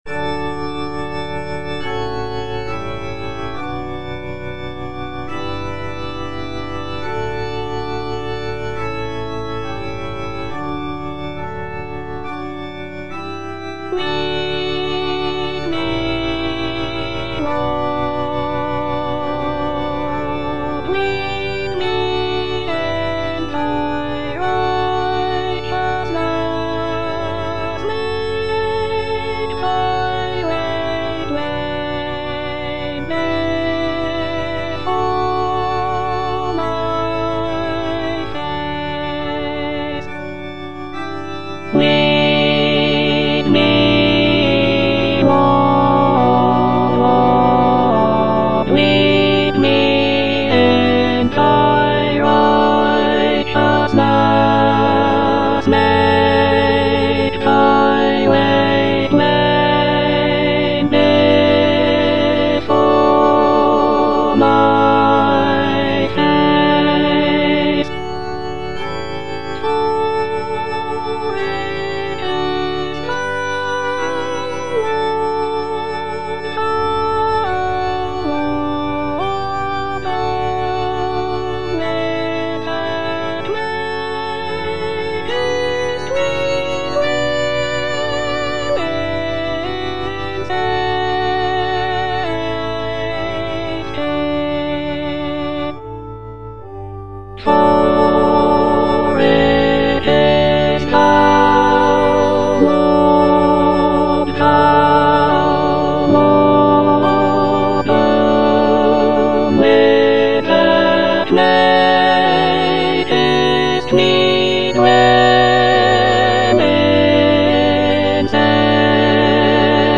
S.S. WESLEY - LEAD ME, LORD Alto (Emphasised voice and other voices) Ads stop: auto-stop
"Lead me, Lord" is a sacred choral anthem composed by Samuel Sebastian Wesley in the 19th century.
The music is characterized by lush choral textures and expressive dynamics, making it a popular choice for church choirs and worship services.